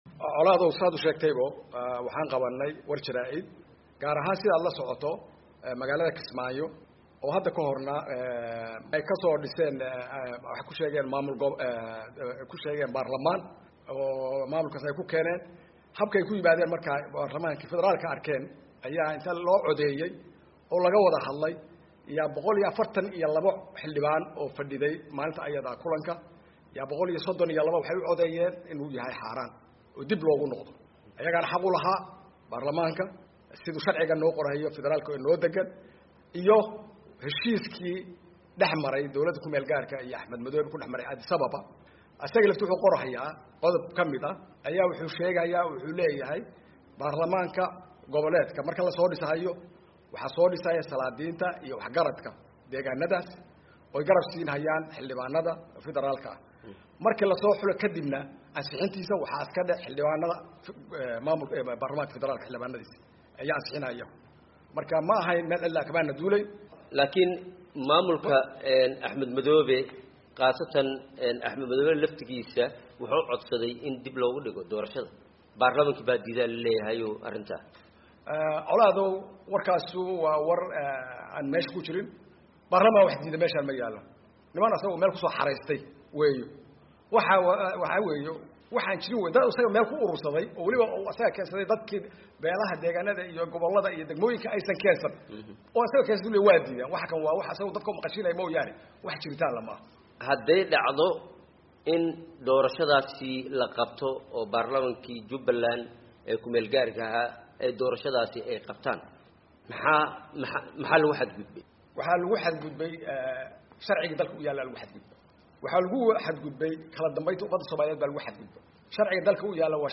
Wareysiga Xildhibaan Magan